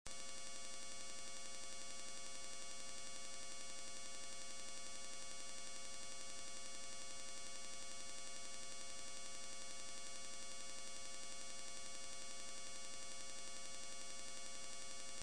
15kHz.wav (2,5 MB),
15kHz.mp3